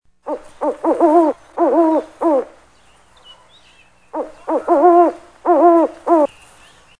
LECHUZA NOCHE DE LECHUZAS SONIDO
Ambient sound effects
Lechuza_Noche_de_Lechuzas_Sonido.mp3